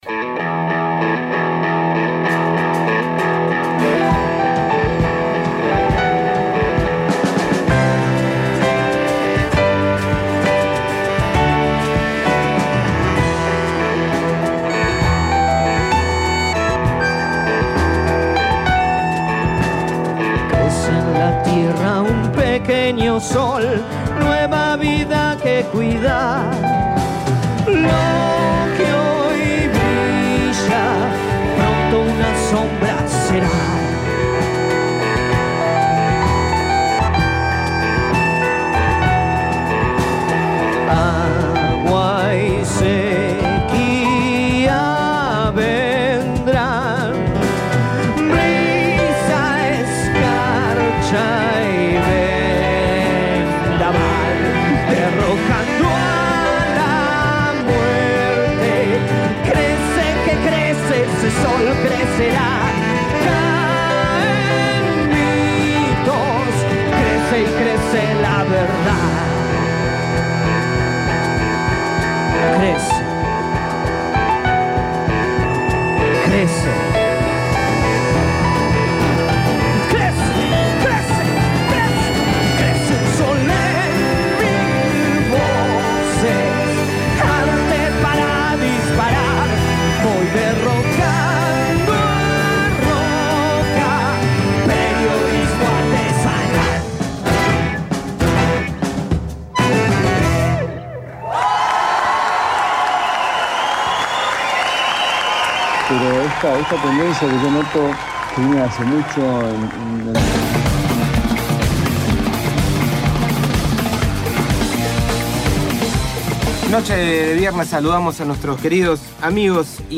(Hasta el minuto 11:43) _ Informe Especial: El Modelo Pepsico. Cuando la rentabilidad, la burocracia sindical y el estado (en forma de represión) confluyen como modelo capitalista salvaje.